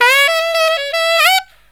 63SAXMD 04-R.wav